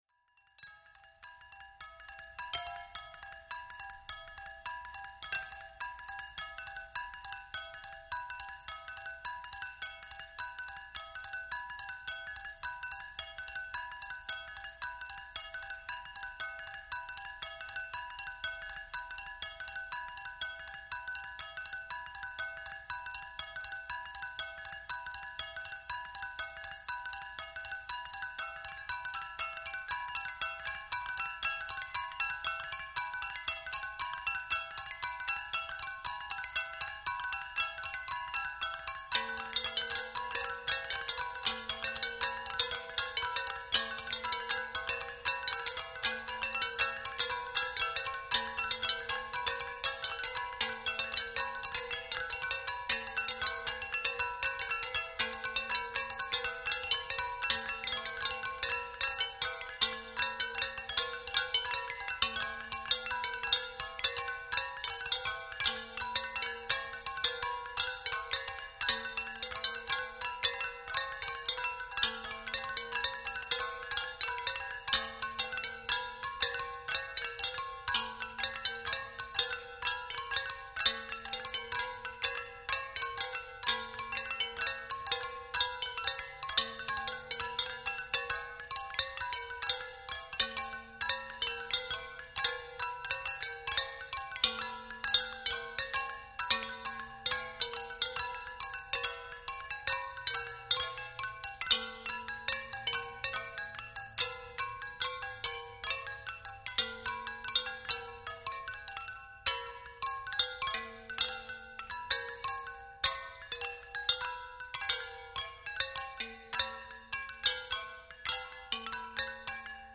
Ghatam.mp3